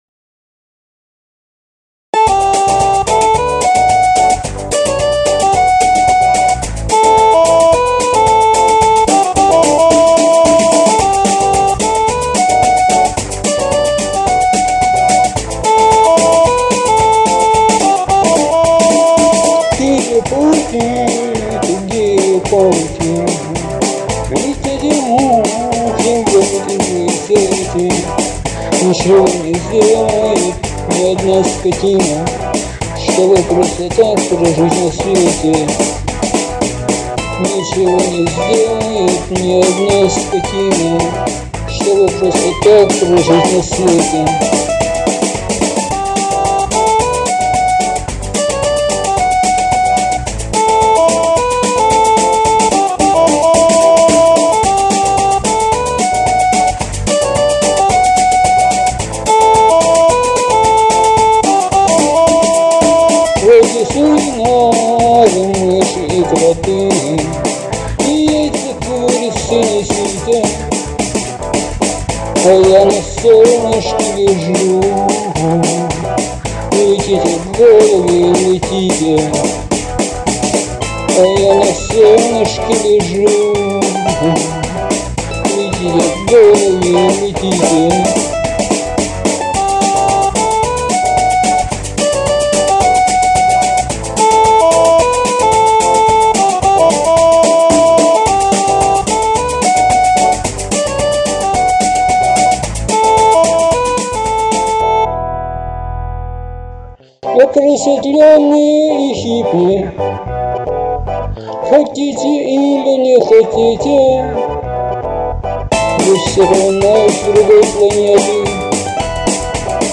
• Жанр: Кантри